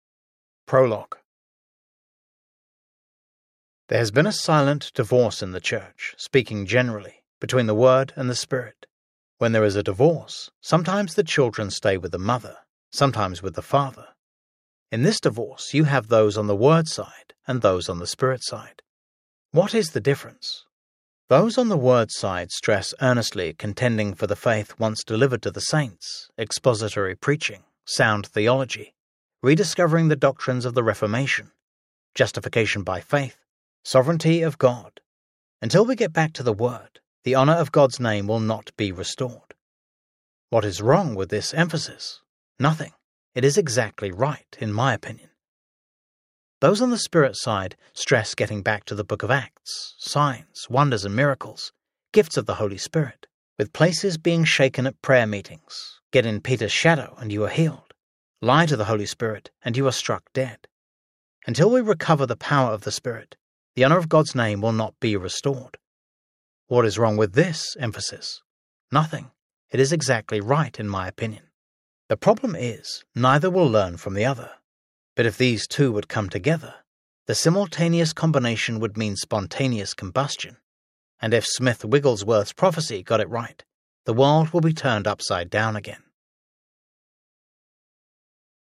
Holy Fire Audiobook
7.52 Hrs. – Unabridged